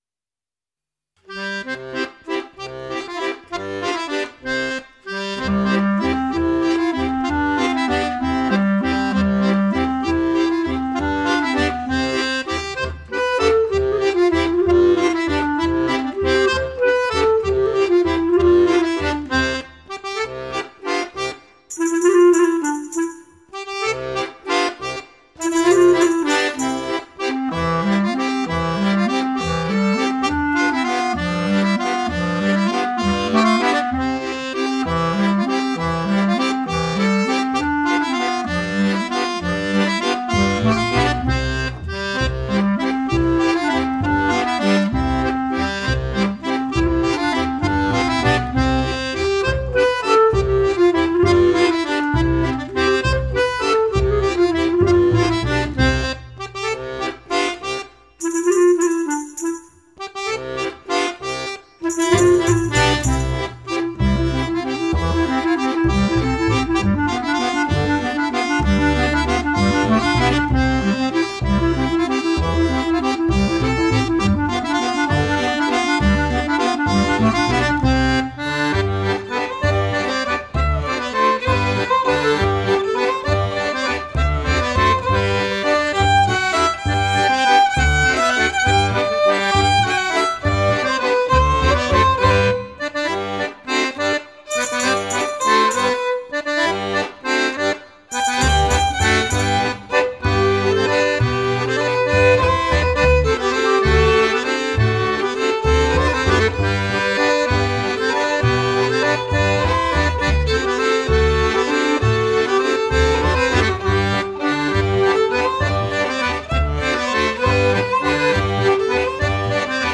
valse à changement de partenaires 3'57